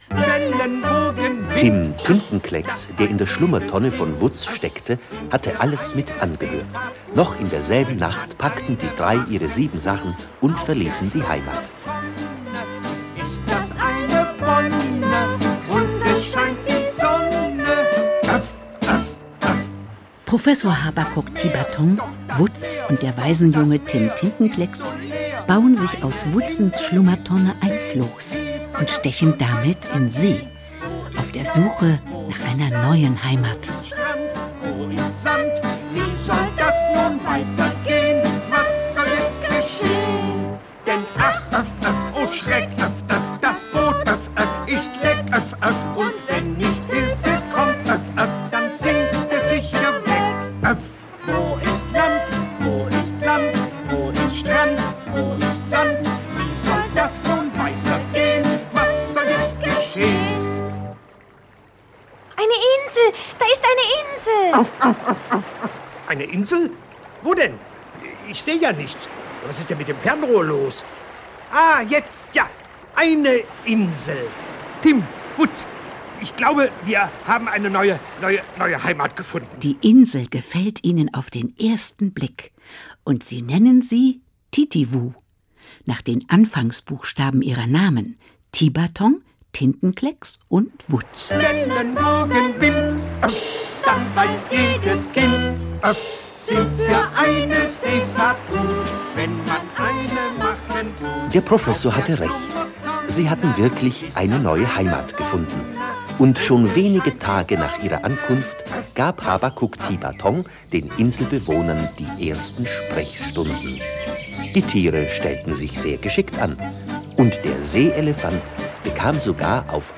Hörbuch Augsburger Puppenkiste - Urmel aus dem Eis.